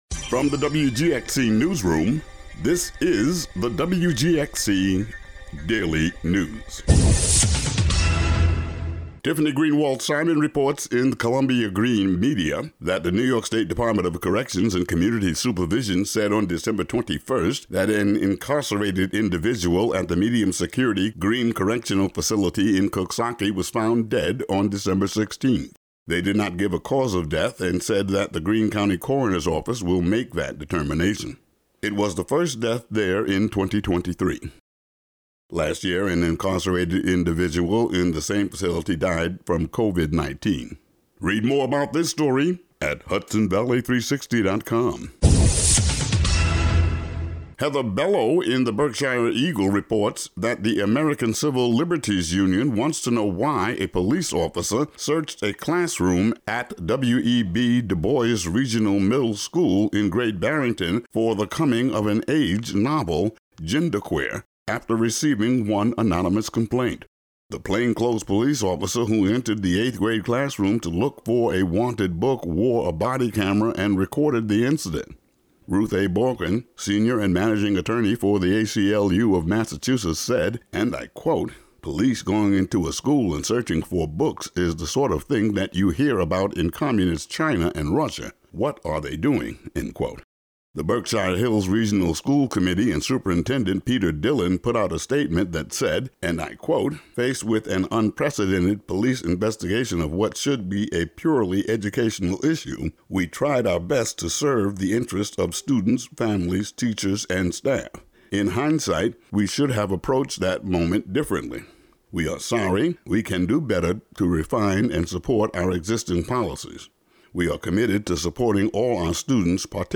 Today's daily local audio news.